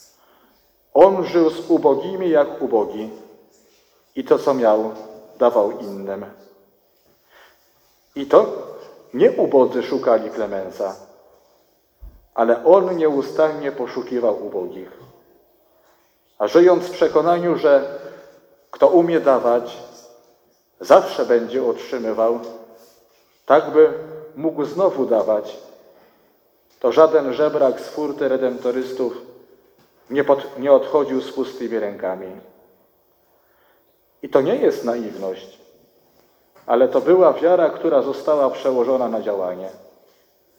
fragmenty homilii audio: